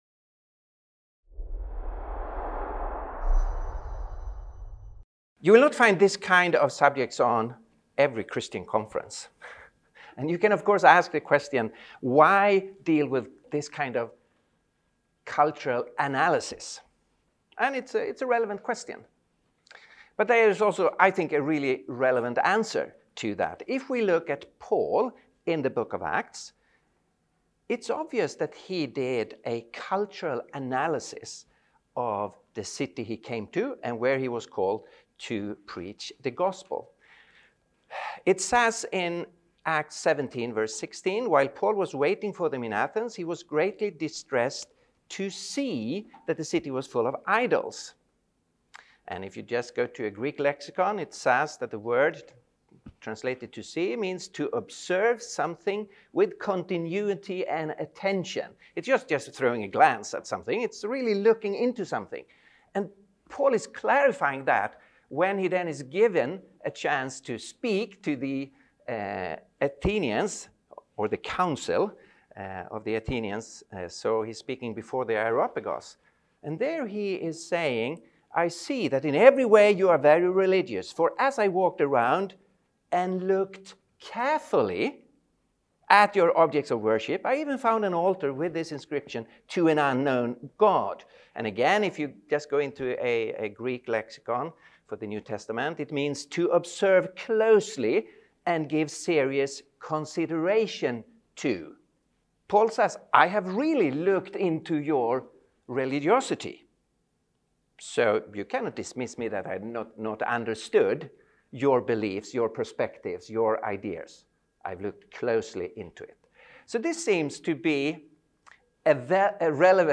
Elements of postmodernism are shifting, and a new cultural framework could be emerging. This talk explores the rise of metamodernism and what it means for truth, meaning, and faith.
Event: ELF Workshop